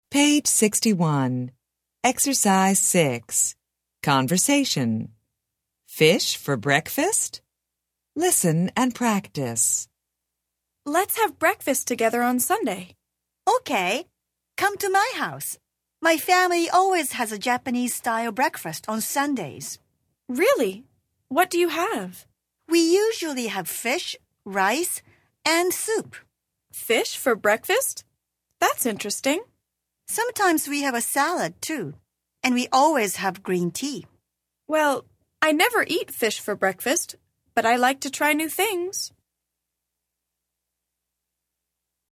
Interchange Third Edition Intro Unit 9 Ex 6 Conversation Track 26 Students Book Student Arcade Self Study Audio
interchange3-intro-unit9-ex6-conversation-track26-students-book-student-arcade-self-study-audio.mp3